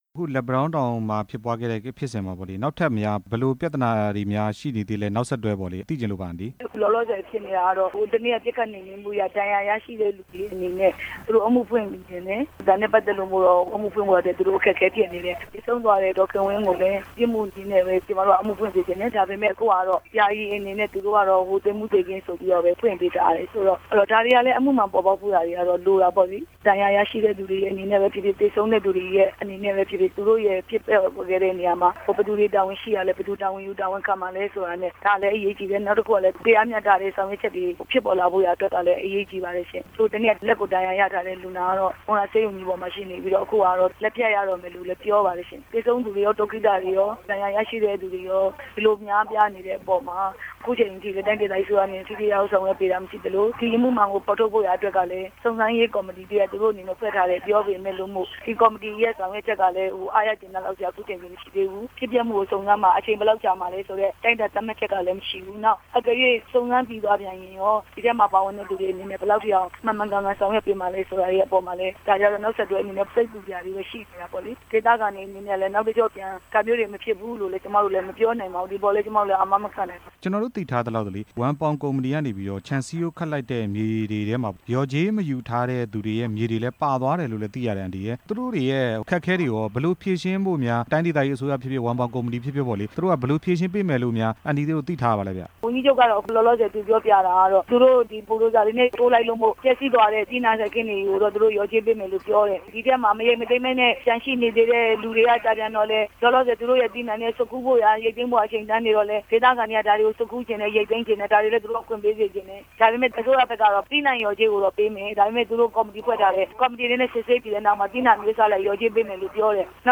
လက်ပံတောင်းတောင် အကြမ်းဖက်မှုတွေ့ရှိချက် အကြောင်း မေးမြန်းချက်